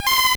Cri de Wattouat dans Pokémon Or et Argent.